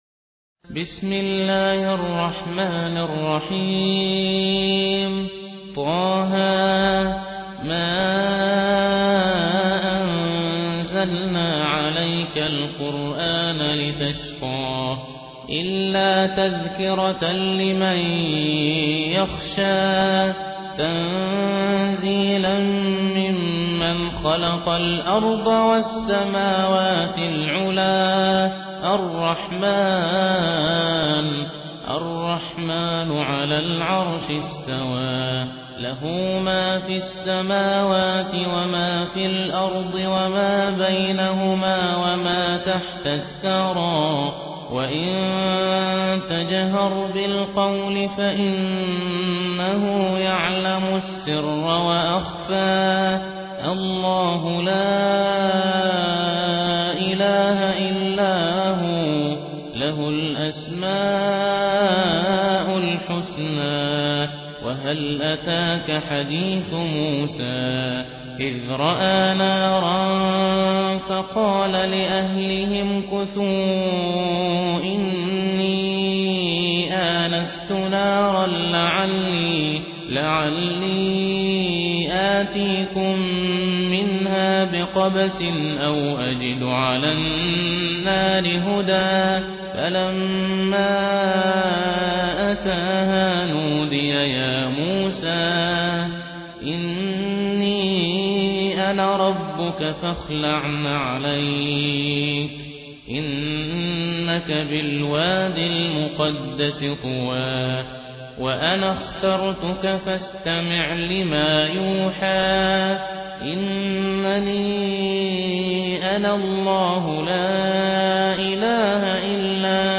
موقع إسلامي متخصص في الصوتيات الدروس والتلاوات والأناشيد ذات الجودة والنقاوة العالية